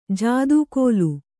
♪ jādū kōlu